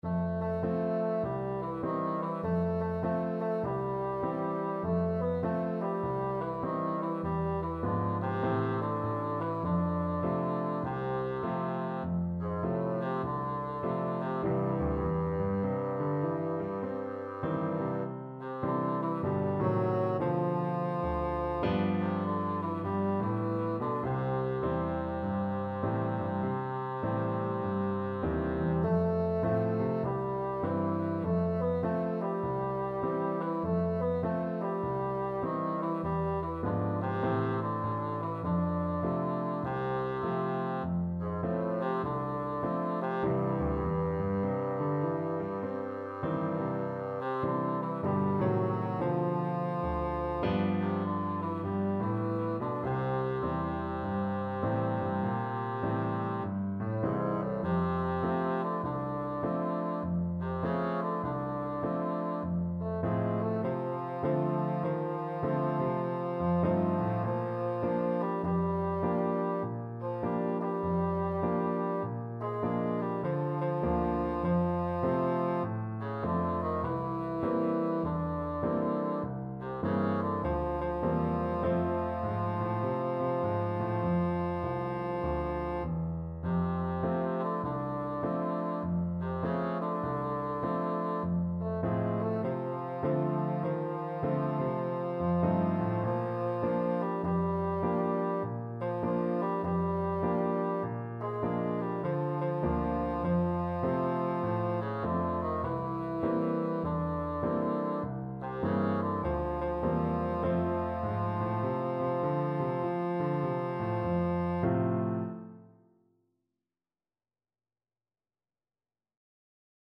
4/4 (View more 4/4 Music)
Swung
Jazz (View more Jazz Bassoon Music)